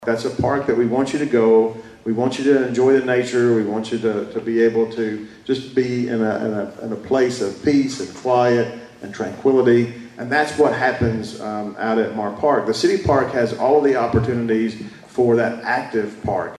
During last week’s State of the Cities and County Address, Madisonville Mayor Kevin Cotton and Hopkins County Judge-Executive Jack Whitfield shared updates on local projects, including the addition of the new extension office at Mahr Park Arboretum.